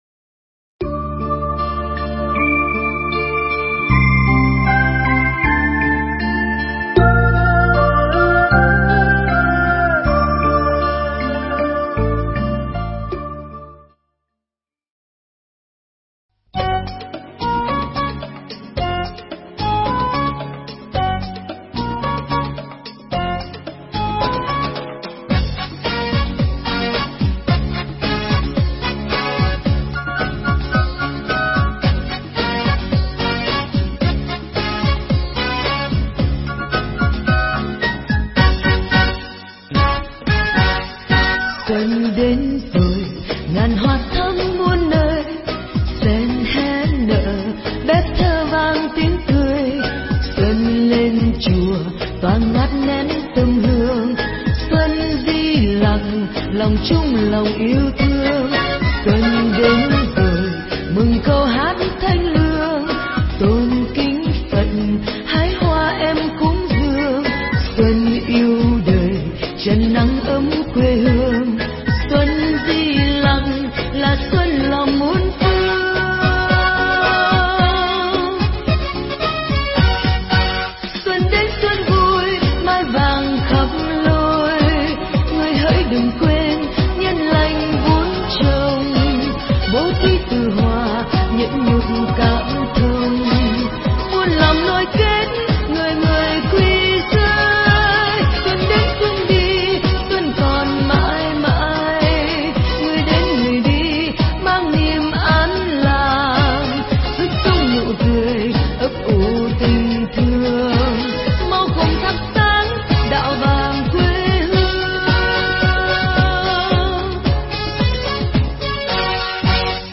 Nghe Mp3 thuyết pháp Chớ Bảo Xuân Tàn Hoa Rụng Hết